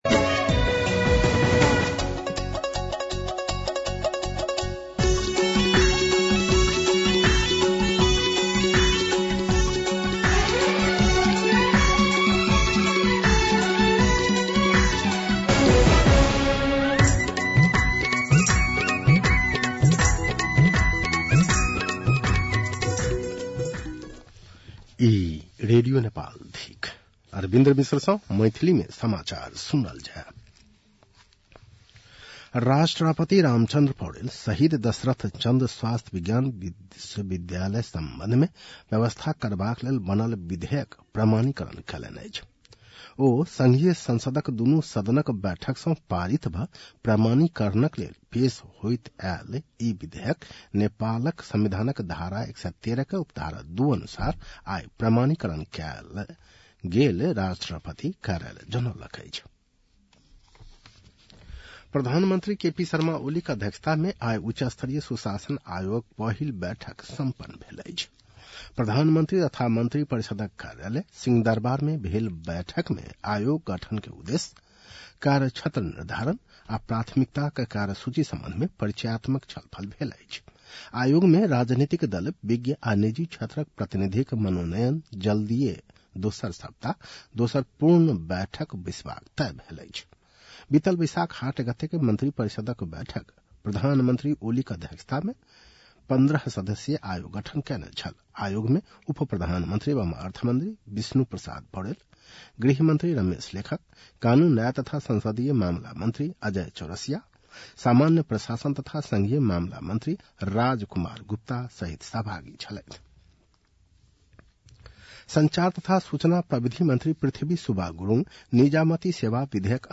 An online outlet of Nepal's national radio broadcaster
मैथिली भाषामा समाचार : ४ असार , २०८२
Maithali-NEWS-03-04.mp3